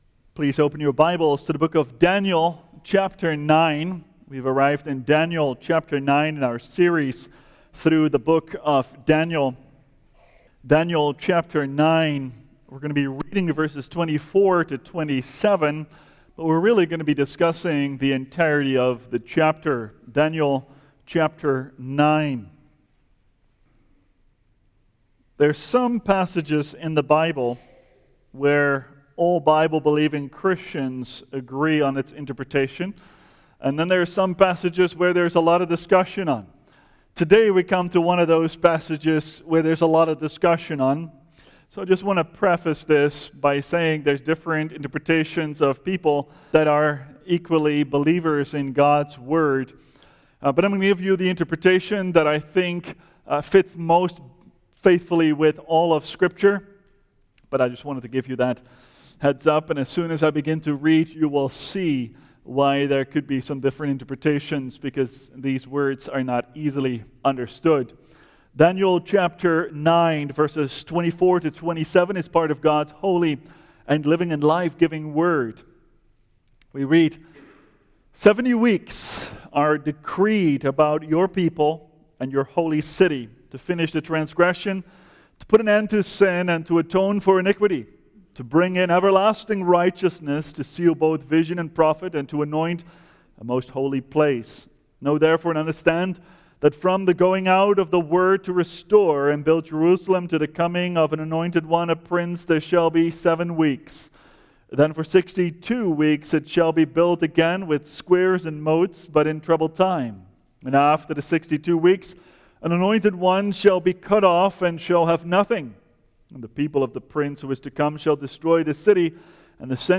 preaches Daniel’s prayer, the 70 sevens, the first 7 sevens, the 62 sevens, and the last seven.